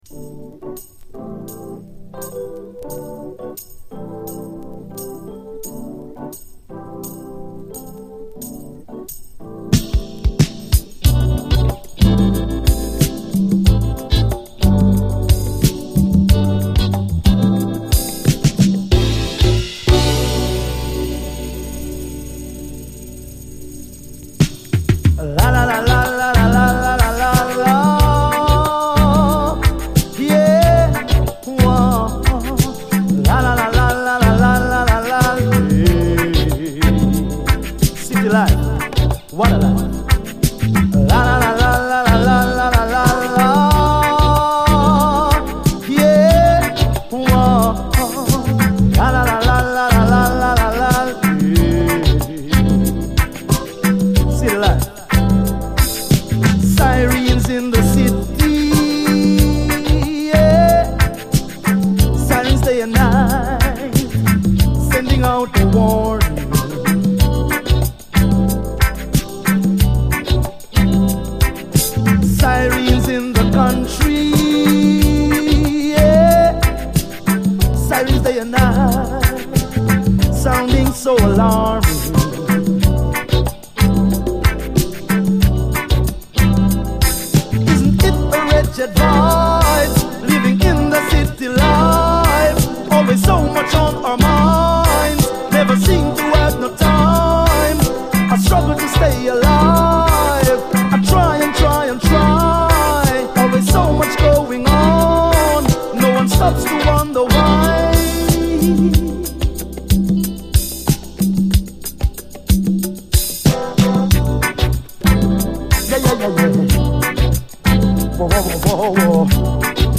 REGGAE
UKルーツを象徴する洗練された浮遊サウンドが炸裂した傑作メロウ・レゲエ
ラヴァーズ好きにも大スイセン、甘美なるフローティン・サウンドを堪能あれ！